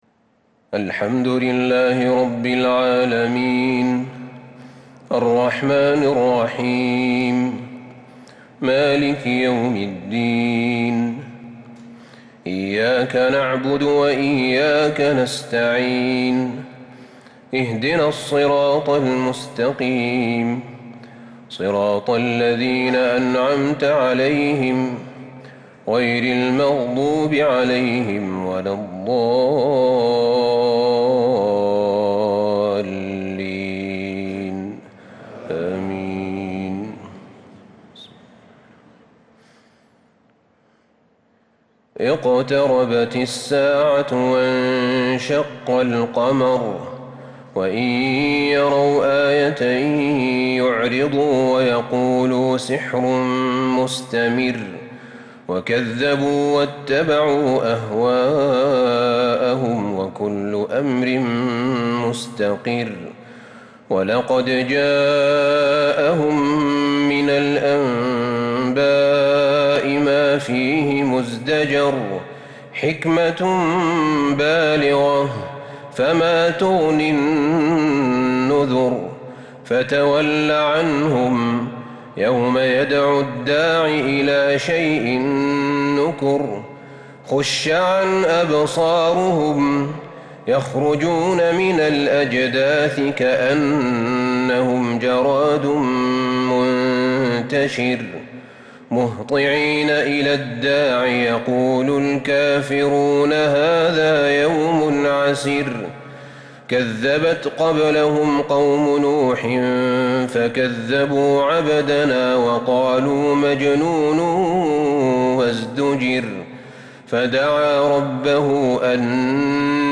صلاة التراويح l ليلة 28 رمضان 1442 | سور القمر والرحمن والواقعة | taraweeh prayer The 28th night of Ramadan 1442H | > تراويح الحرم النبوي عام 1442 🕌 > التراويح - تلاوات الحرمين